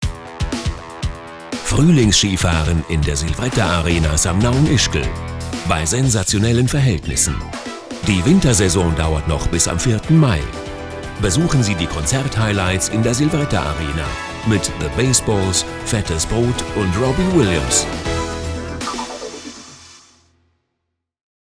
Werbung Hochdeutsch (CH)
Sprecher mit breitem Einsatzspektrum.